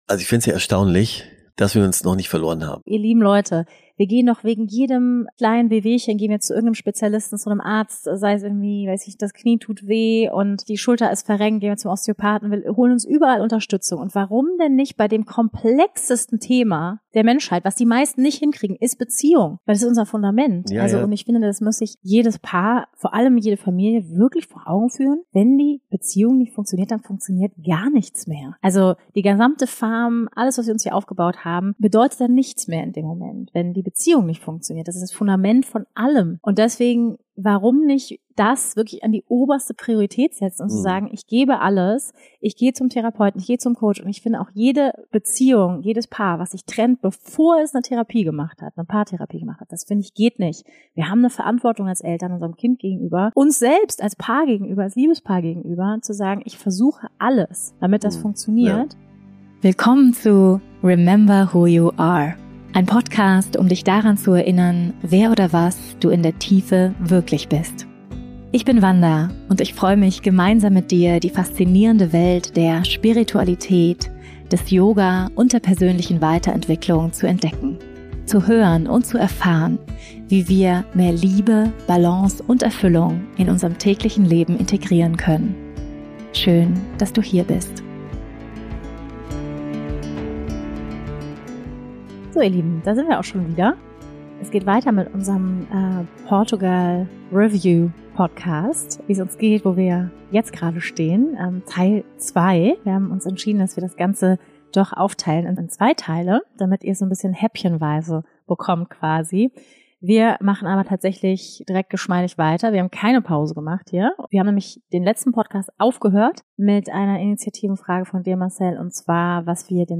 Ein persönliches Gespräch